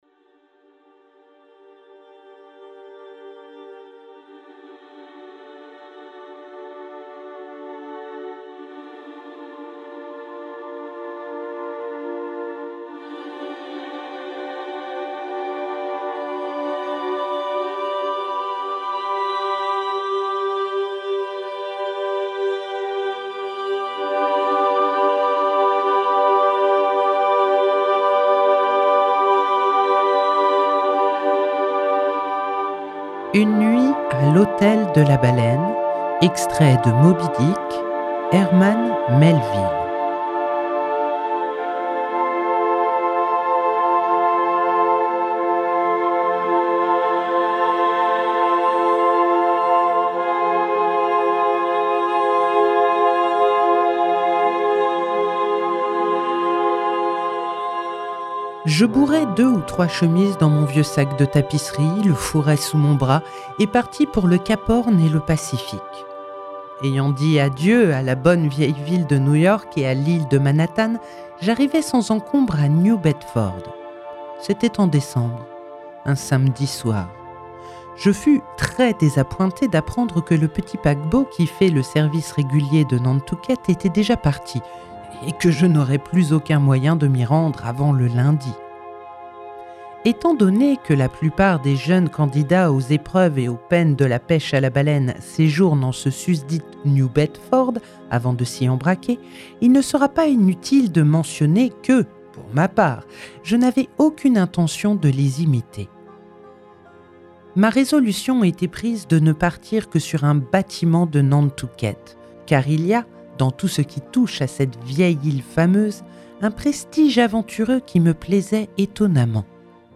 🎧 Une nuit à l’hôtel de la Baleine – Herman Melville - Radiobook